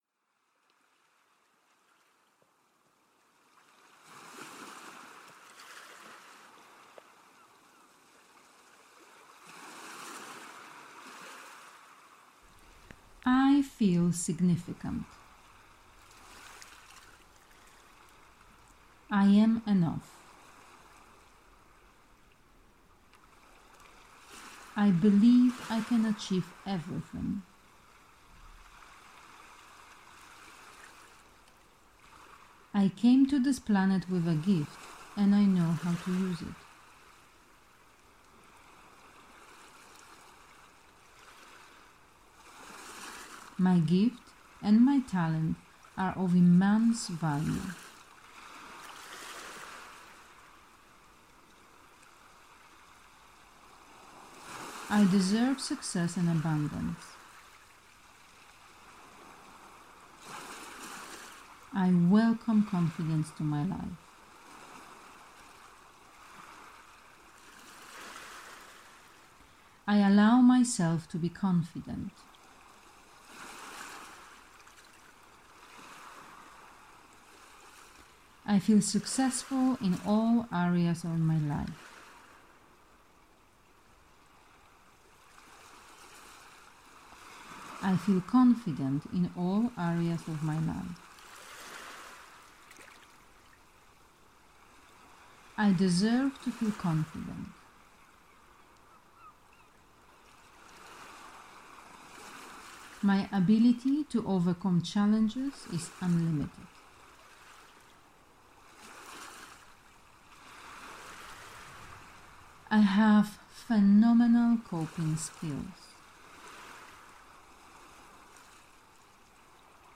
There is a moment of silence between each statement so you can repeat it out loud or in your mind.
affirmations-for-confidence.mp3